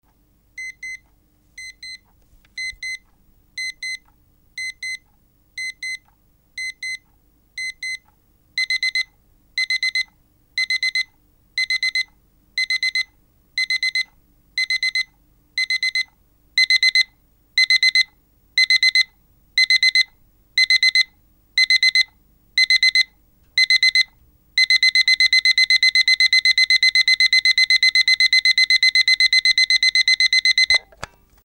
Timbre de un despertador
timbre
alarma
despertador
Sonidos: Hogar